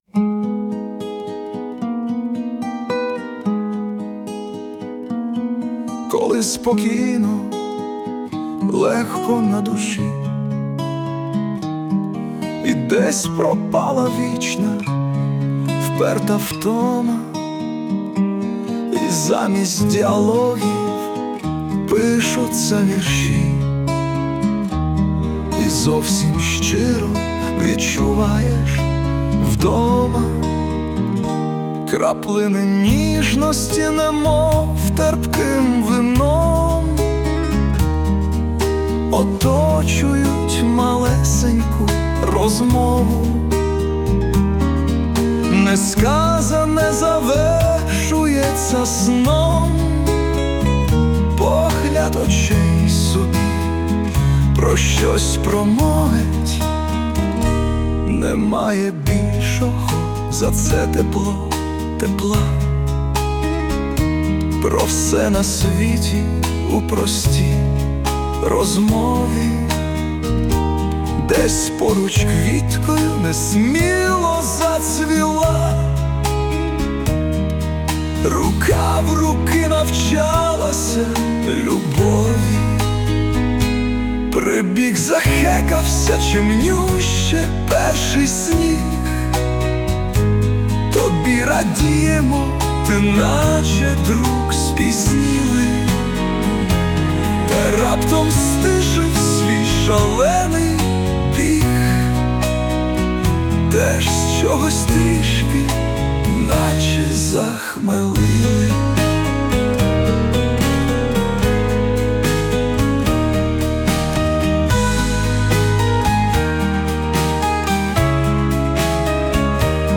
Присутня допомога SUNO
це мабуть важливо, щоб десь так випадково зовсім чулася гітара ...